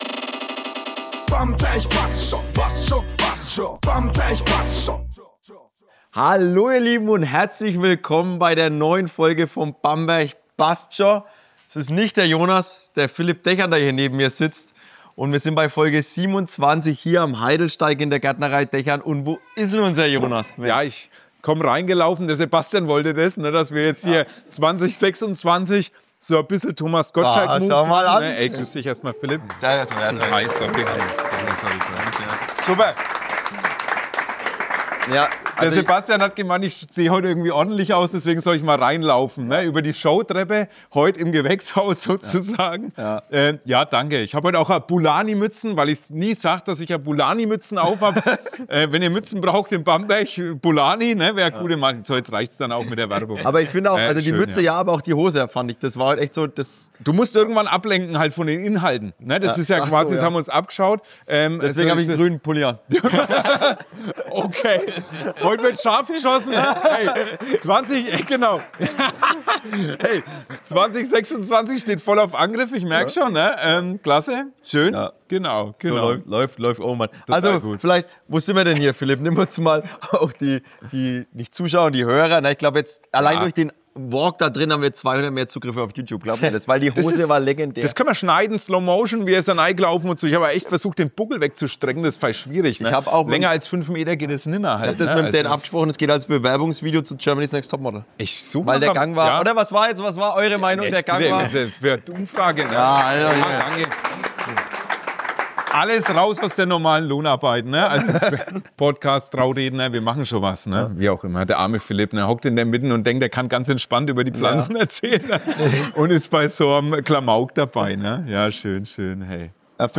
Dazu gibt’s viele Anekdoten, ehrliche Einblicke, einen kleinen Jahresrückblick und natürlich jede Menge ungefilterten Podcast-Klamauk. Eine Folge über Wurzeln, Wachstum und das, was Bamberg ausmacht.